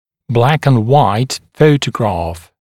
[ˌblækən(d)’waɪt ‘fəutəgrɑːf][ˌблэкэн(д)’уайт ‘фоутэгра:ф]чёрно-белая фотография